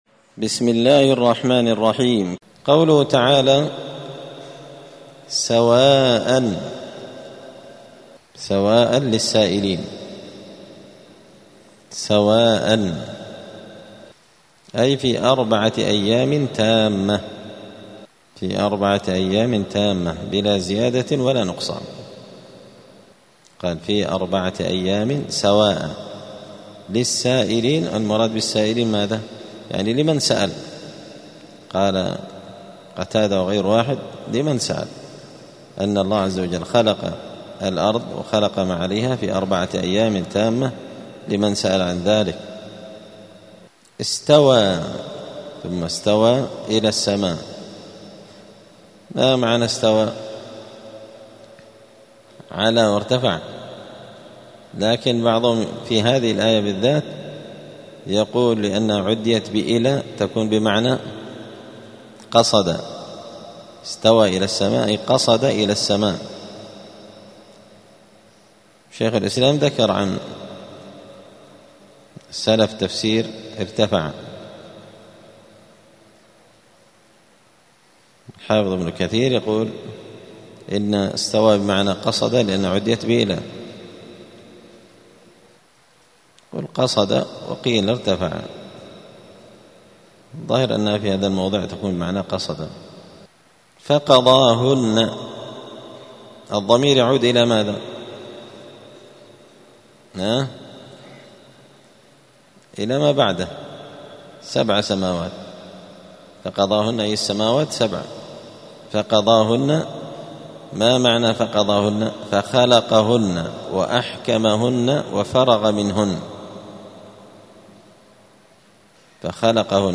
زبدة الأقوال في غريب كلام المتعال الدرس السادس عشر بعد المائتين (216)
216الدرس-السادس-عشر-بعد-المائتين-من-كتاب-زبدة-الأقوال-في-غريب-كلام-المتعال.mp3